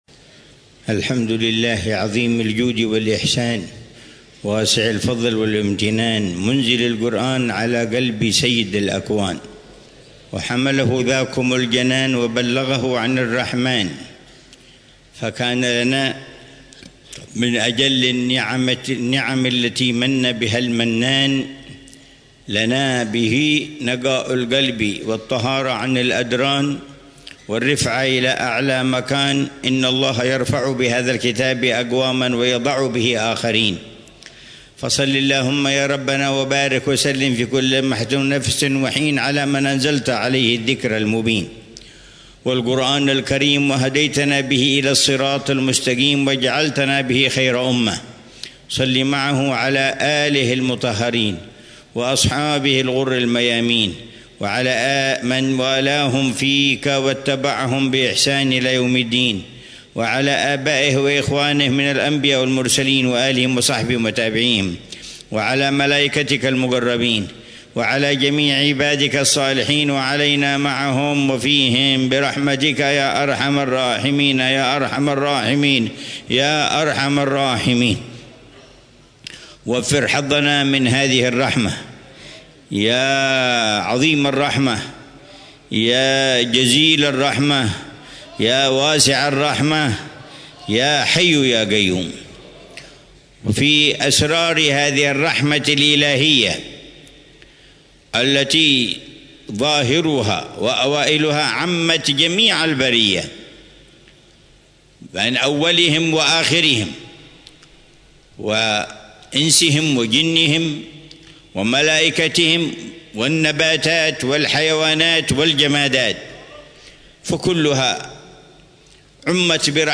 محاضرة العلامة الحبيب عمر بن محمد بن حفيظ ضمن سلسلة إرشادات السلوك في دار المصطفى، ليلة الجمعة 13 ربيع الأول 1447هـ، بعنوان: